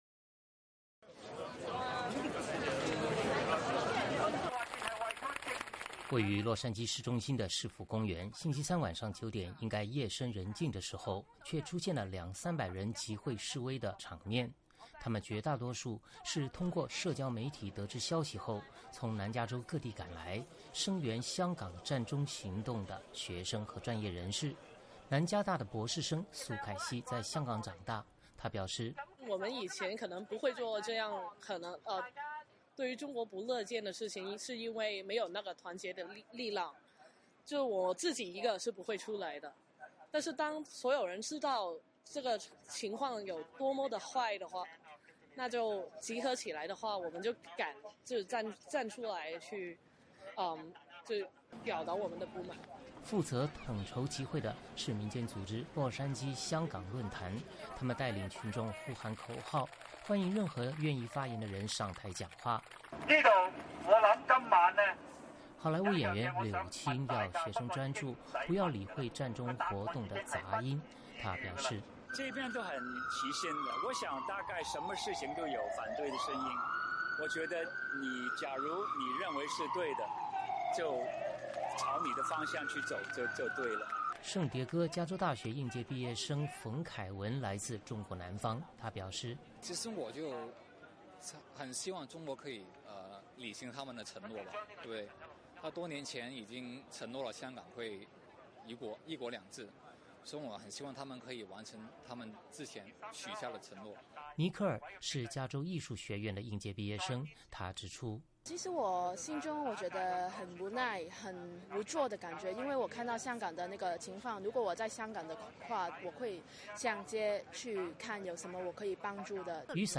南加州地区的香港人星期三声援香港占中行动，在中国国庆日的夜里发出响亮的呼喊。位于洛杉矶市中心的市府公园，星期三晚上九点应该夜深人静的时候，却出现了两三百人集会示威的场面。